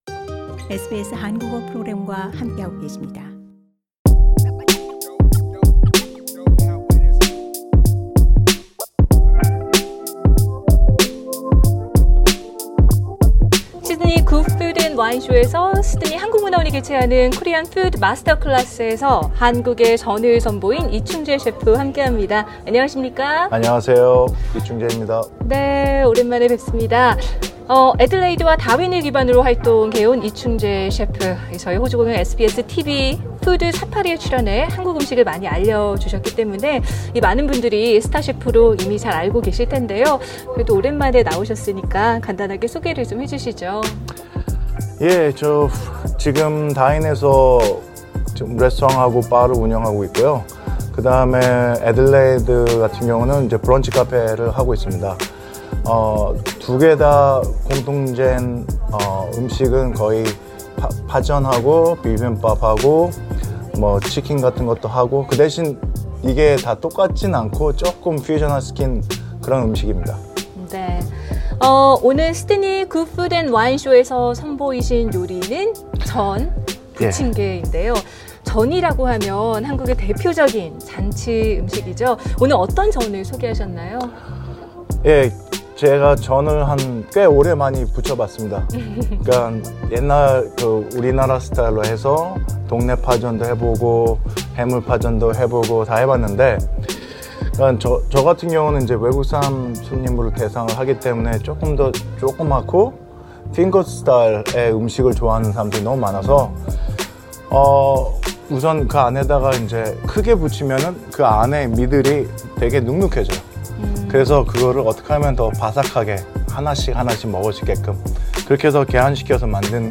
굿 푸드 앤 와인쇼 현장에서